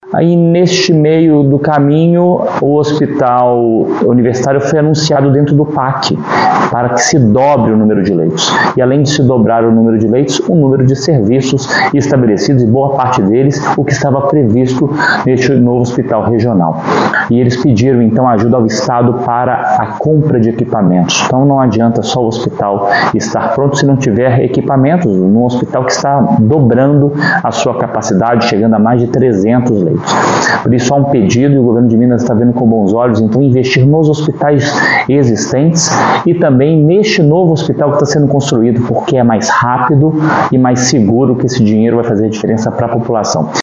Por outro lado, os R$ 150 milhões que seriam para o Hospital Regional serão repassados para o Hospital Universitário da Universidade Federal de Juiz de Fora (HU-UFJF), como explica Fábio Baccheretti.